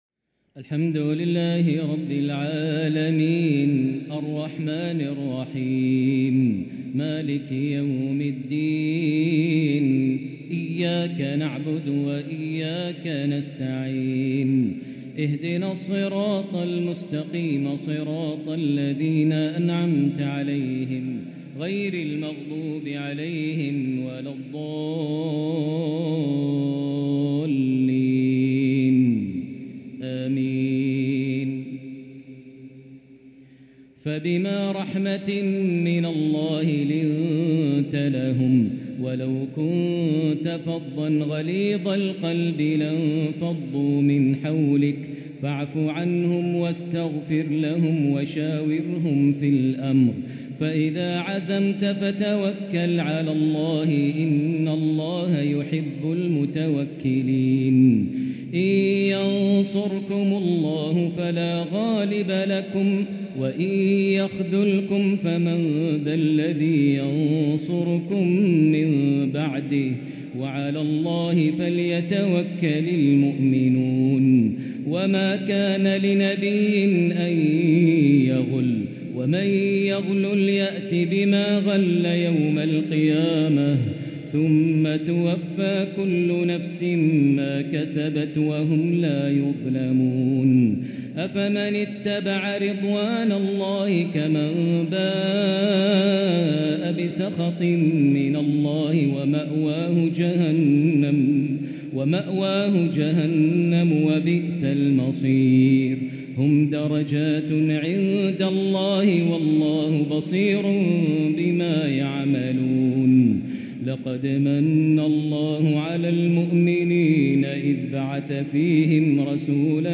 Taraweeh 5st night Ramadan 1444H Surah Aal-i-Imraan > Taraweh 1444H > Taraweeh - Maher Almuaiqly Recitations